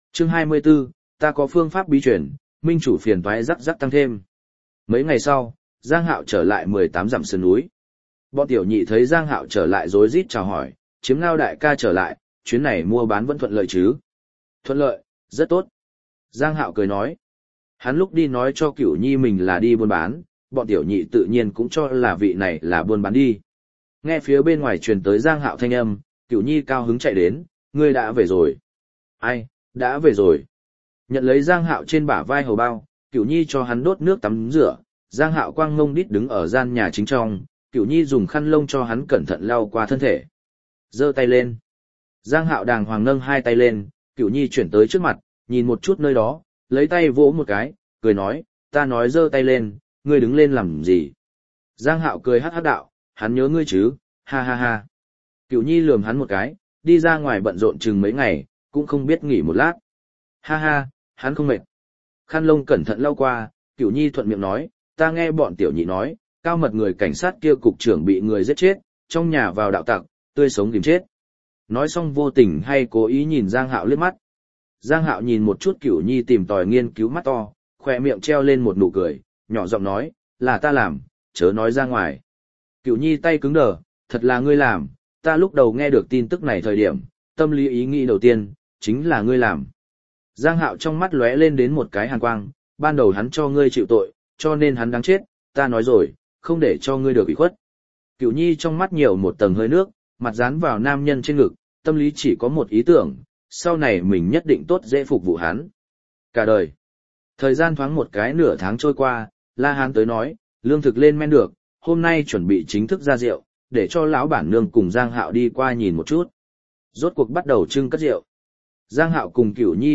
Điện Ảnh Thế Giới Tư Nhân Định Chế Audio - Nghe đọc Truyện Audio Online Hay Trên TH AUDIO TRUYỆN FULL